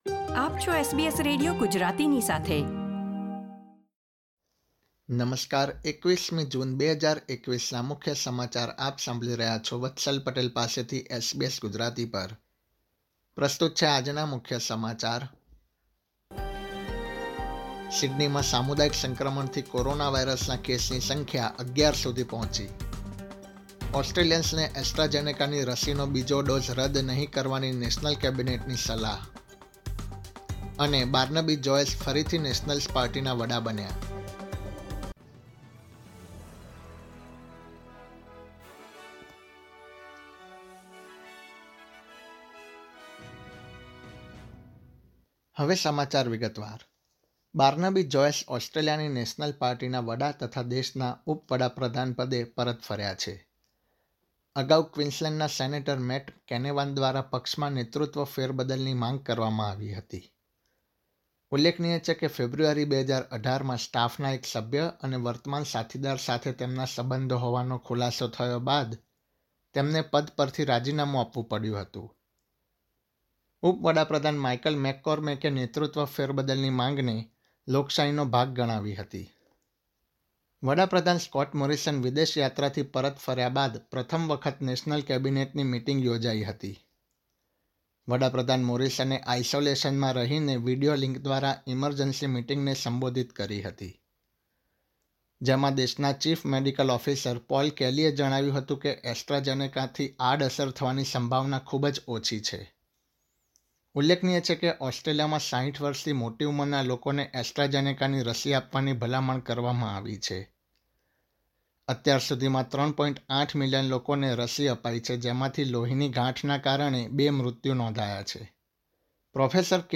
SBS Gujarati News Bulletin 21 June 2021
gujarati_2106_newsbulletin.mp3